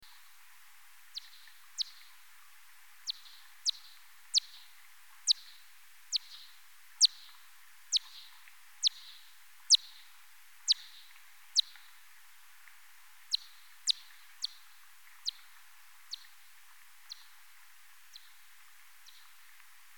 Vattenfladdermus
Amplitudmaximum kring 40 kHz. Ofta ses en S-krök kring 40kHz.
Förväxlingsrisk dammfladdermus: Intervallen mellan pulserna är i regel under 100 ms. Dammfladdermusen som är större har oftare intervall som är över 100 ms och dessutom ligger lägre i frekvens.
Eftersom vattenfladdermusen jagar tätt intill vattenytan så syns interferensen med ekot tydligt på nästa alla inspelningar.
Här är en vattenfladdermus som passerar mikrofonen.